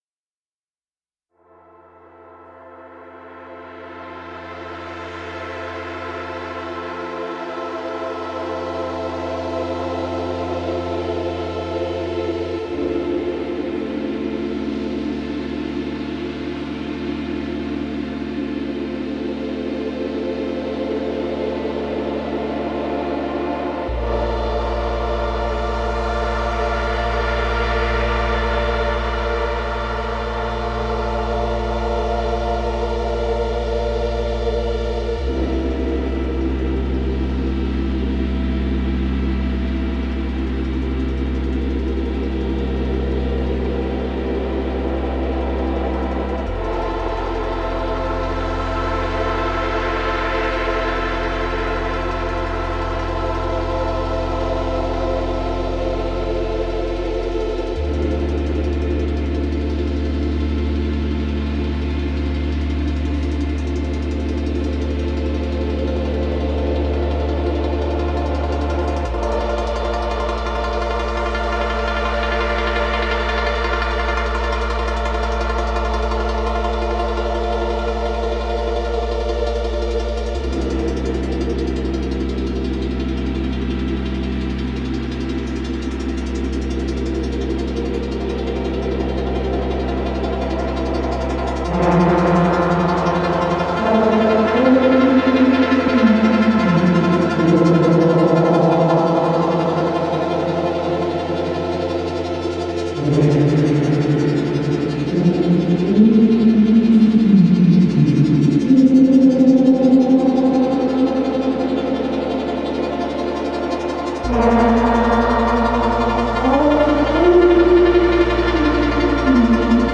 Science Labs : Swarm (the subject) but also Stereo Image and Polyphony.
I wanted to express the Polyphonic and beautiful sides of the Syntakt through the Swarm Machines.